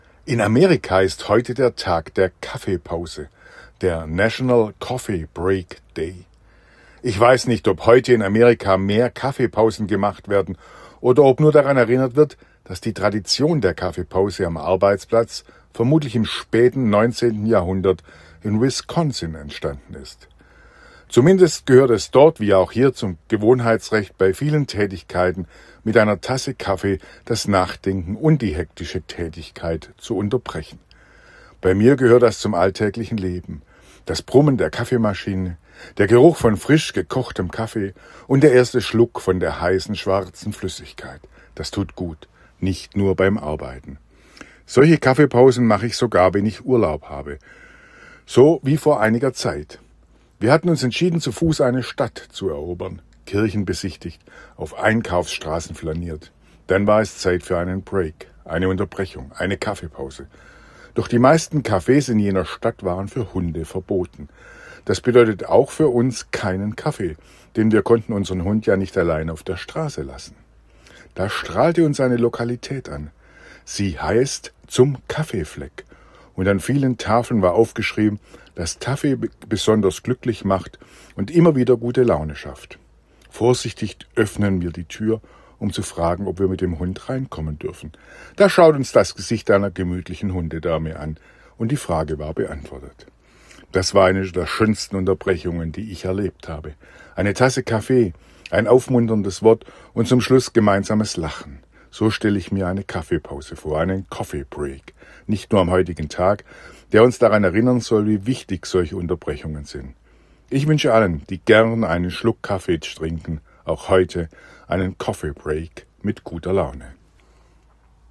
Radioandacht vom 20. Januar